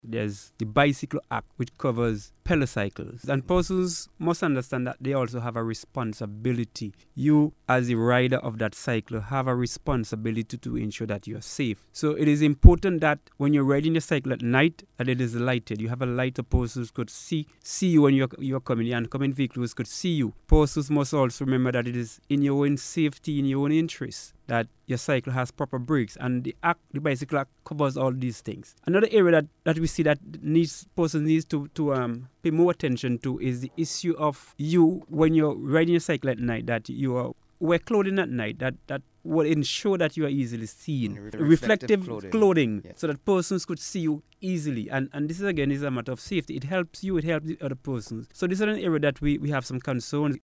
during the Police on the Beat program aired on NBC Radio on Monday evening